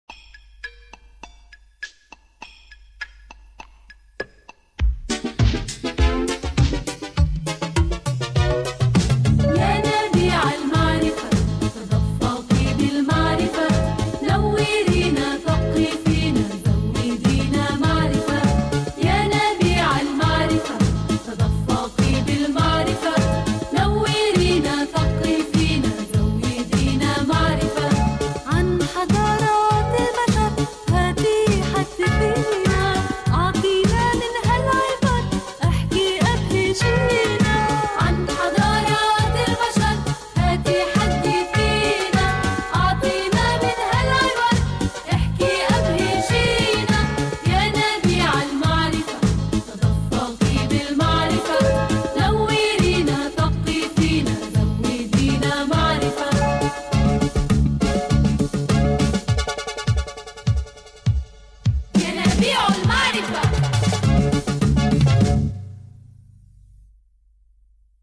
ينابيع المعرفة - الحلقة 1 مدبلجة